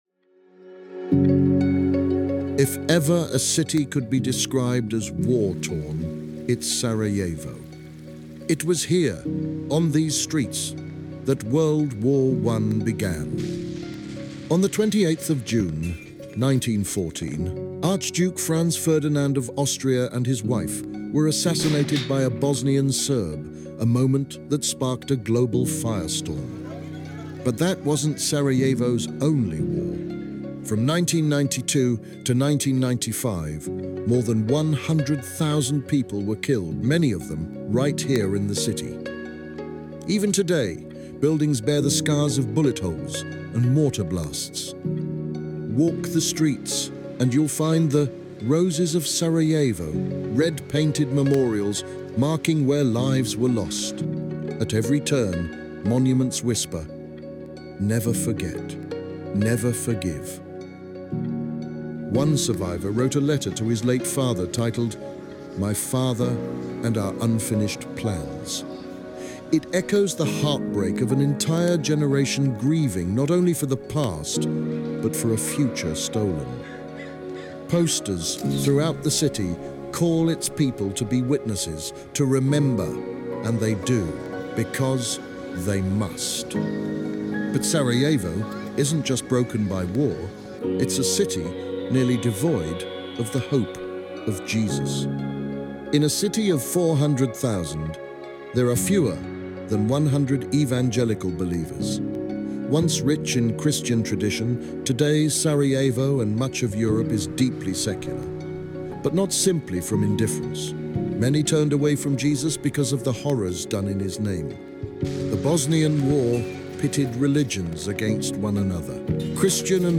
A message from the series "Who Jesus Is."